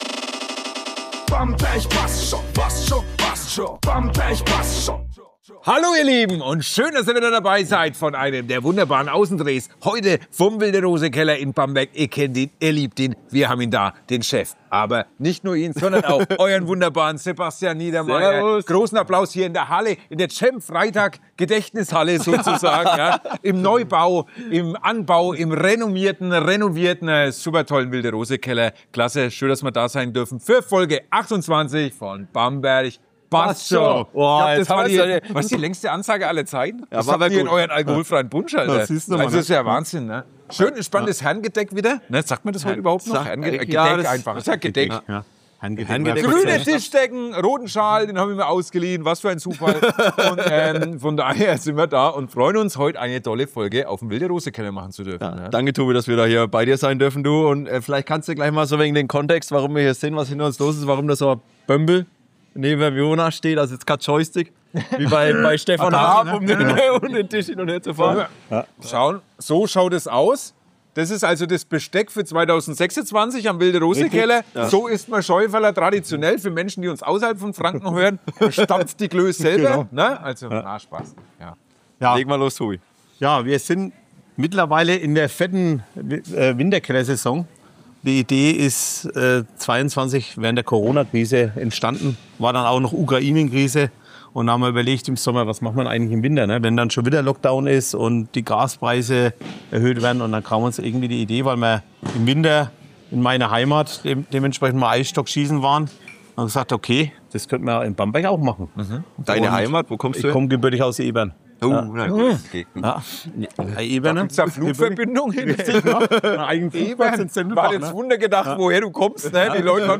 Für diese Episode durften wir an einem ganz besonderen Ort aufnehmen: auf einem der schönsten Bierkeller Deutschlands.